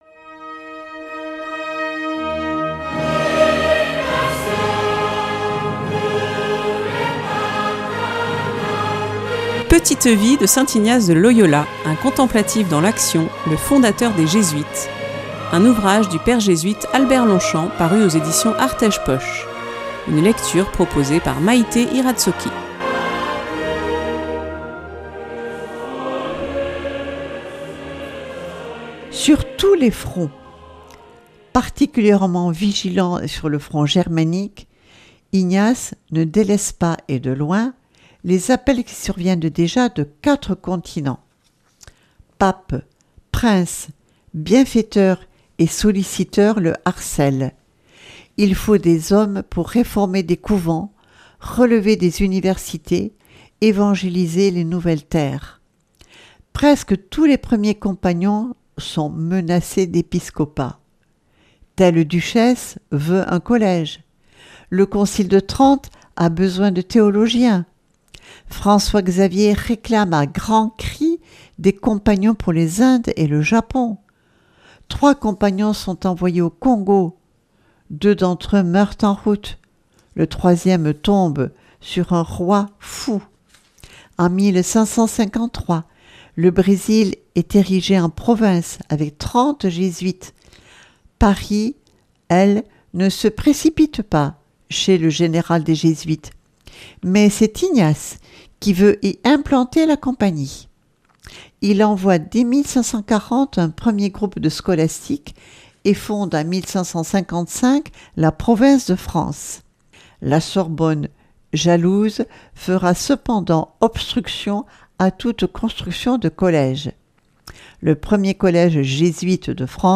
Petite vie de Saint Ignace de Loyola – Albert Longchamp, s.j. (Editions Artège poche) – Une lecture